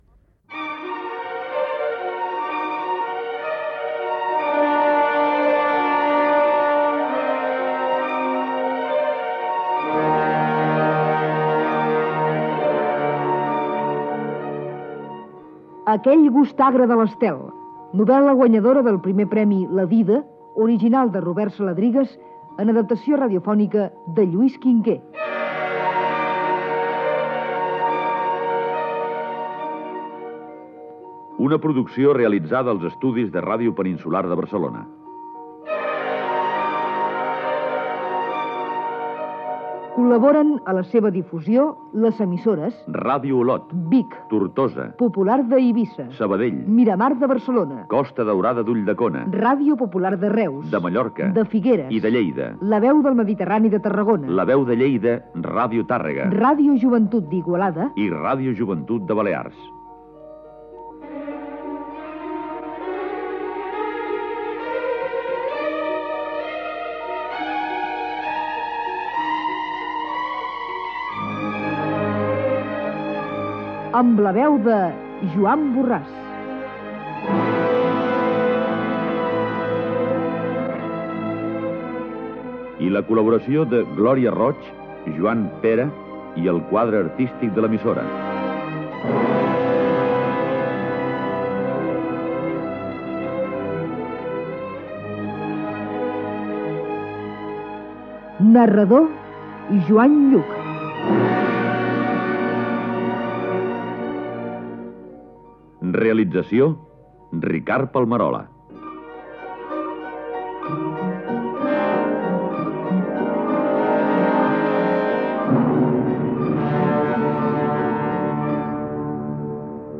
Adaptació radiofònica d'"Aquell gust agre de l'estel", de Robert Saladrigas. Careta del programa amb totes les emissores que estan connectades i el repartiment. Fragment d'un dels capítols
Ficció